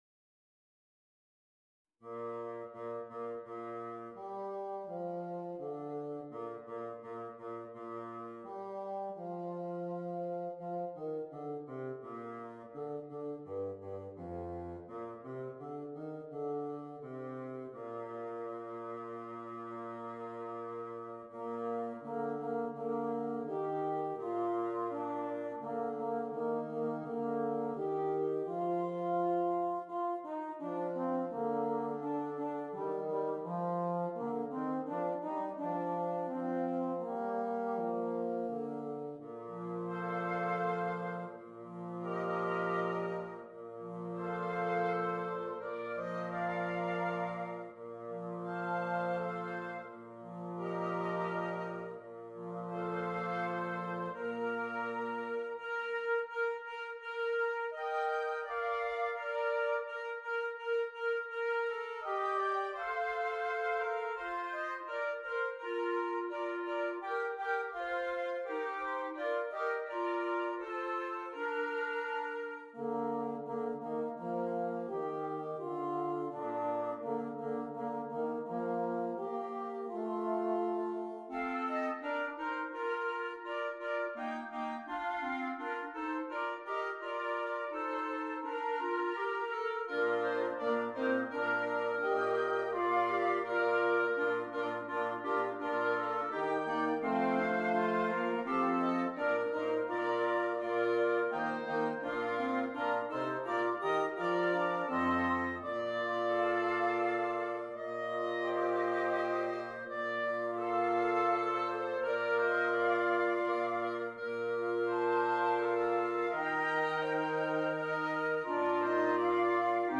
Woodwind Quintet
Traditional Carol
This piece is flowing and smooth, quiet and reflective.